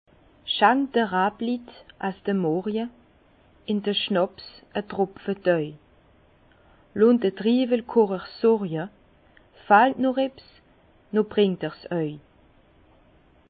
Bas Rhin
Herrlisheim